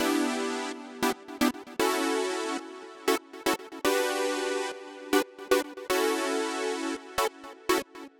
11 Chord Synth PT3.wav